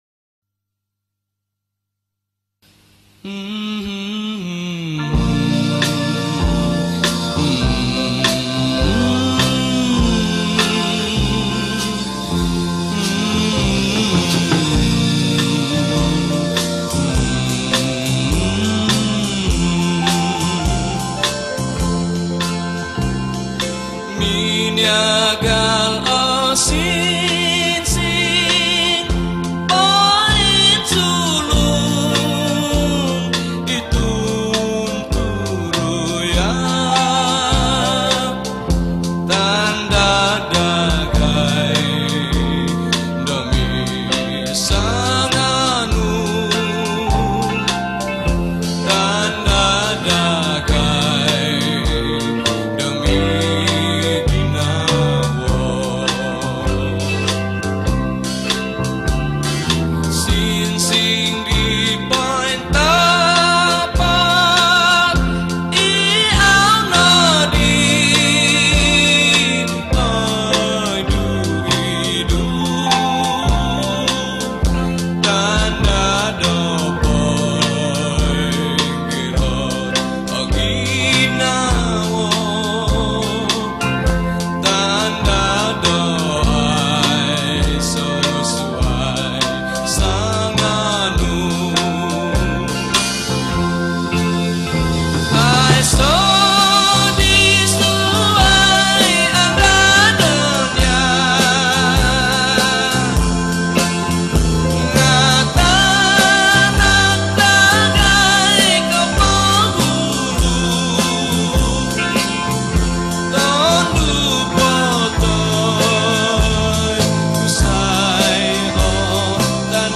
Popular Kadazan Song